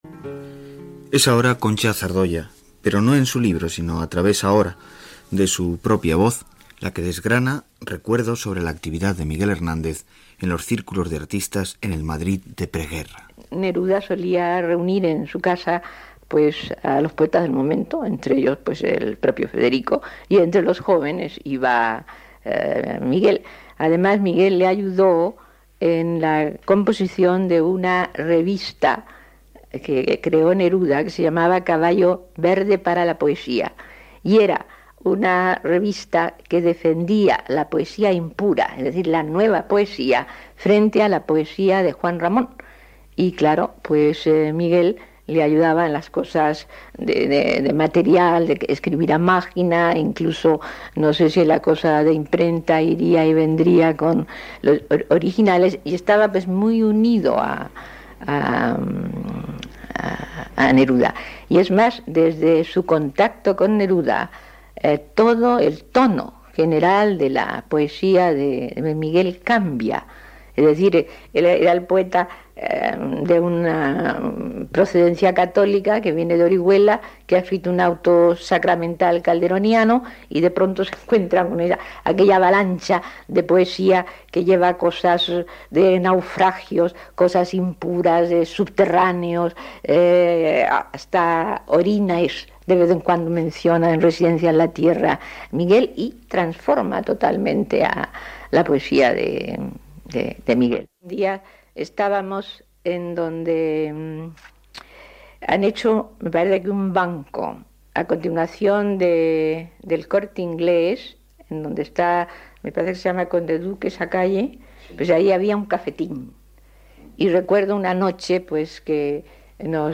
L'escriptora Concha Zardoya recorda al poeta Miguel Hernández